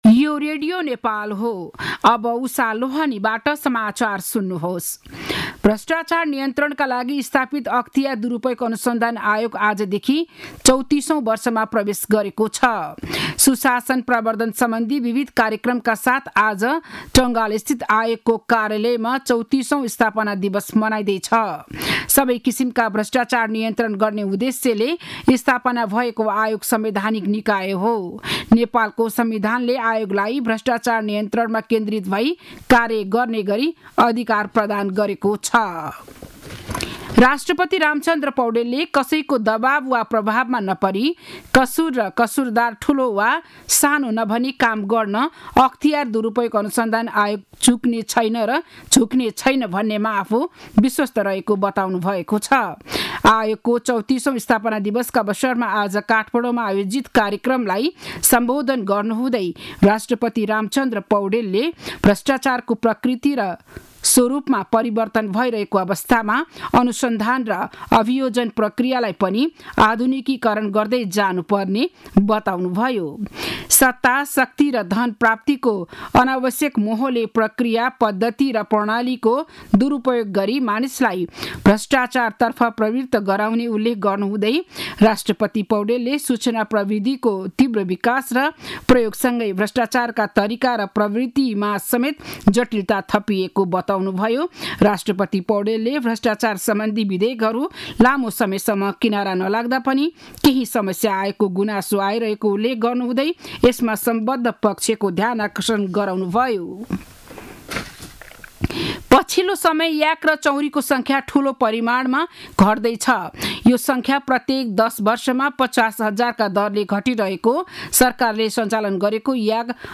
बिहान ११ बजेको नेपाली समाचार : २९ माघ , २०८१
11-am-news-1-4.mp3